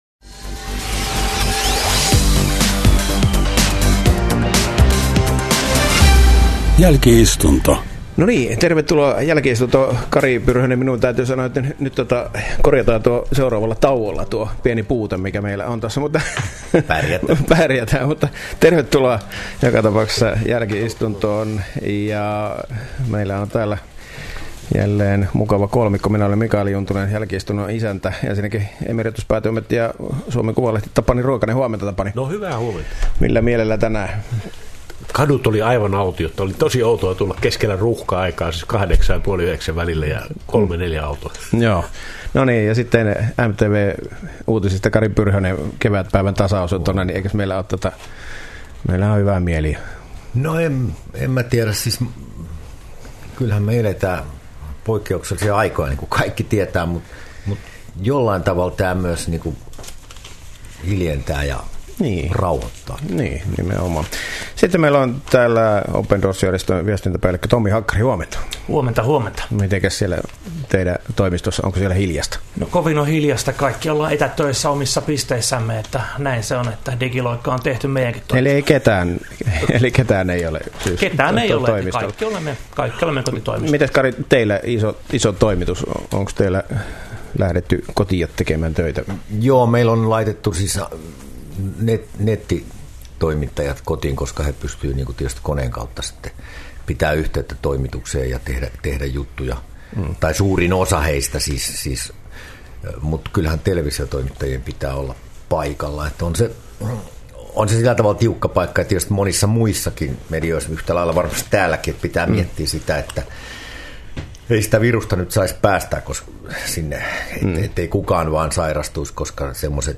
Jälki-istunto kokoaa viikoittain median eri laitoja edustavat keskustelijat studioon saman pöydän ääreen.